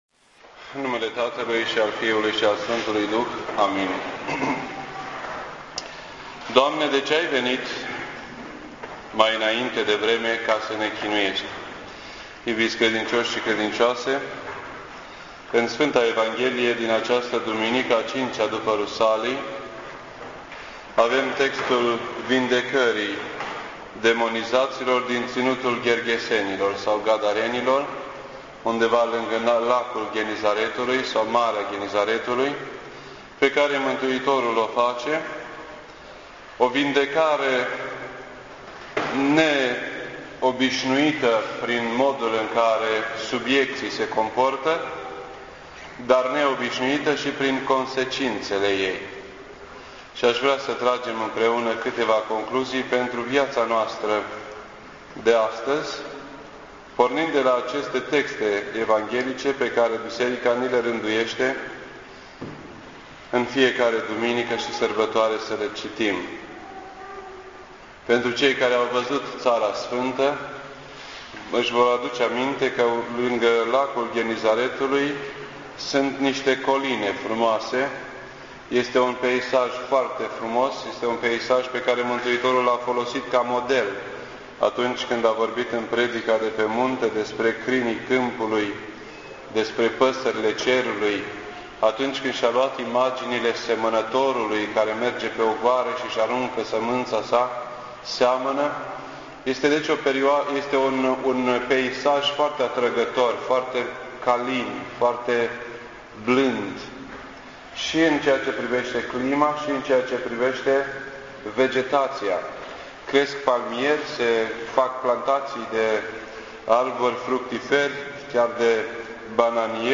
This entry was posted on Sunday, July 20th, 2008 at 9:37 AM and is filed under Predici ortodoxe in format audio.